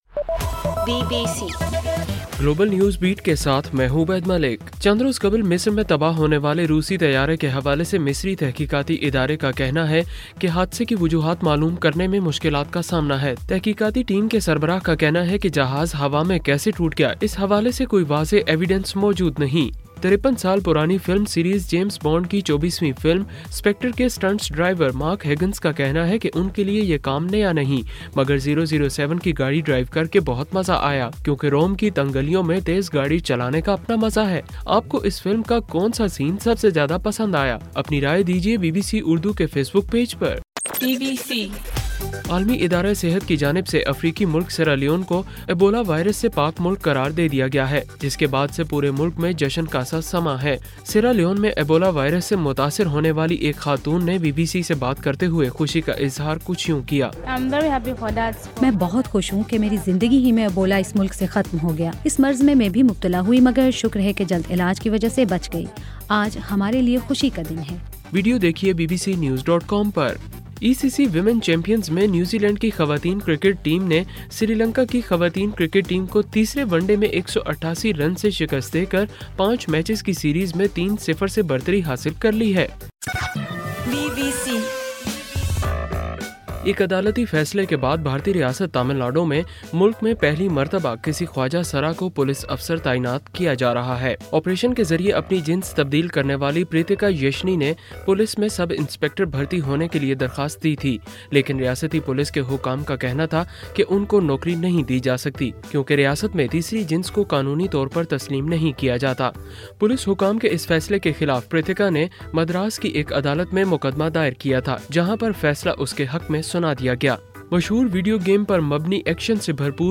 نومبر 8: صبح 1 بجے کا گلوبل نیوز بیٹ بُلیٹن